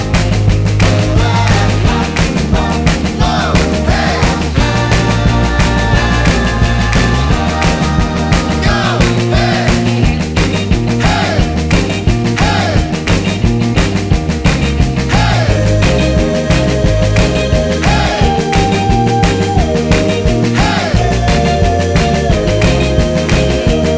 No Lead Saxophone Rock 'n' Roll 2:31 Buy £1.50